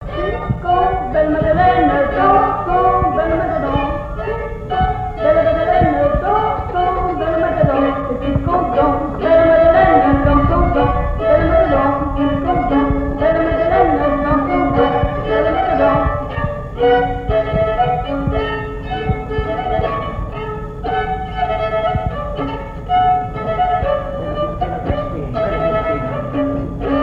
danse : polka piquée
Pièce musicale inédite